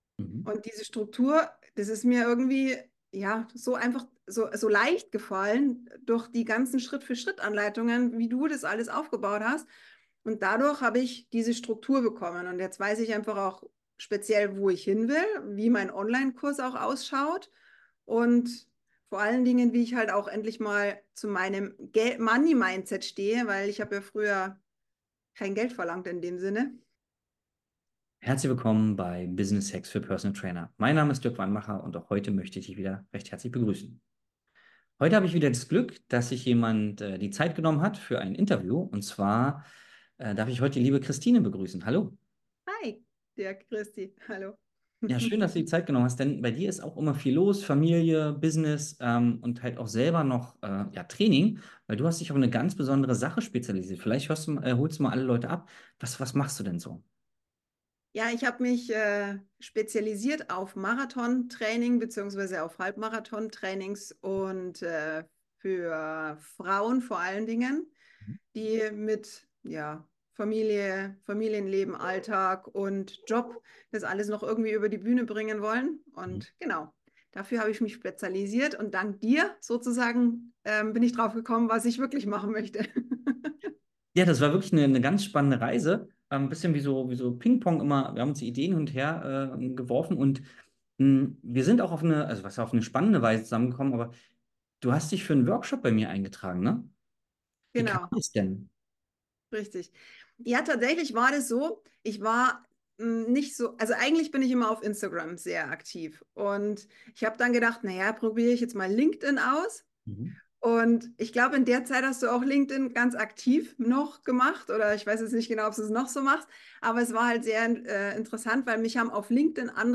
#296 Erfolgreiche Lauftrainerin für Frauen, ein Kundeninterview